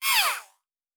pgs/Assets/Audio/Sci-Fi Sounds/Mechanical/Servo Small 1_2.wav at 7452e70b8c5ad2f7daae623e1a952eb18c9caab4
Servo Small 1_2.wav